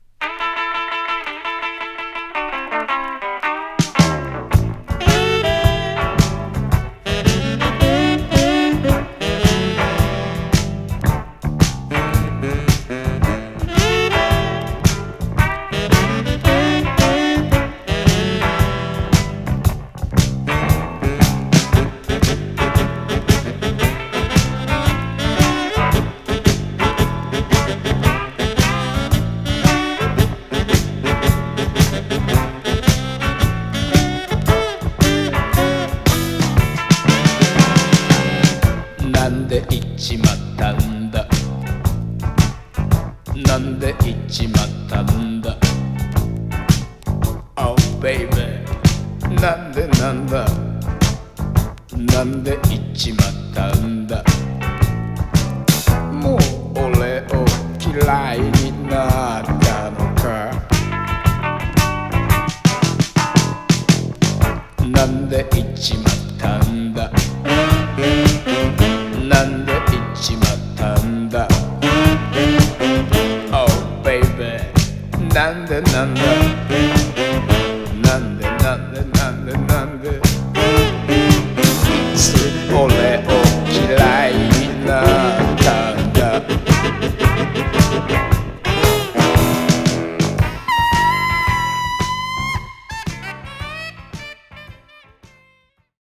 ポップでジャズでアバンギャルドな名盤